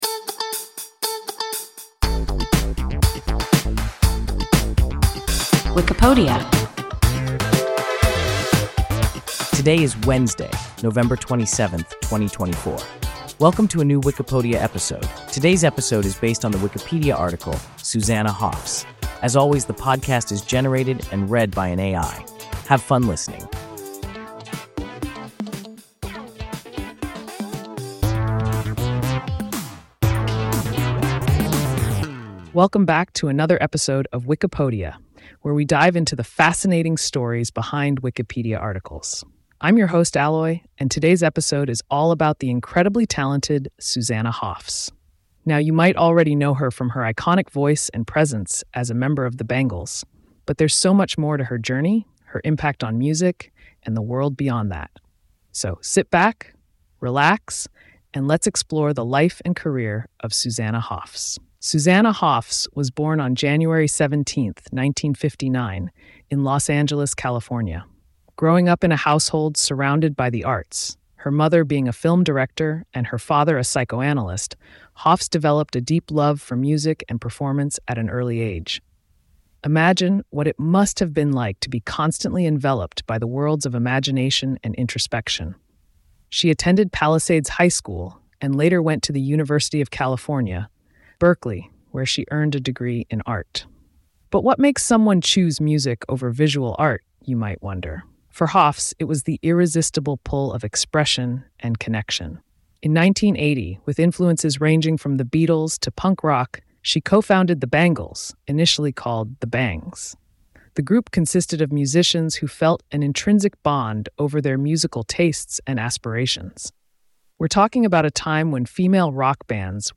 Susanna Hoffs – WIKIPODIA – ein KI Podcast